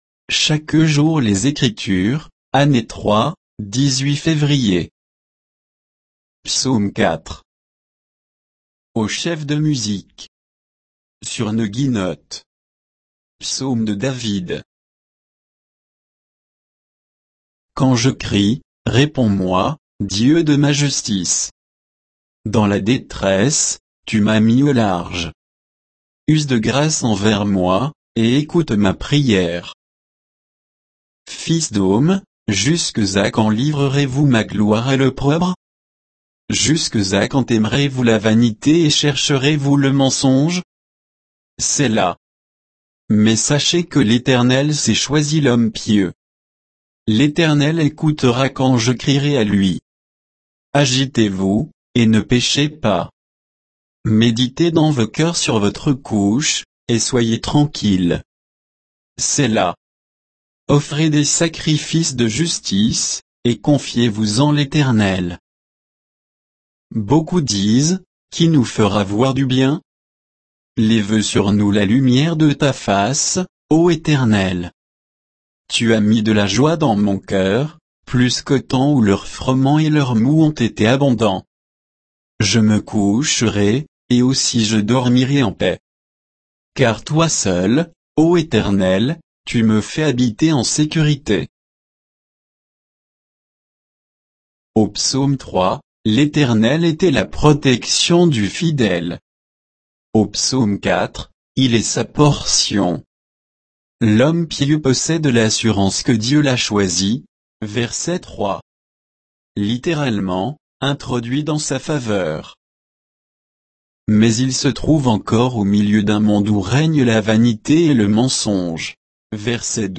Méditation quoditienne de Chaque jour les Écritures sur Psaume 4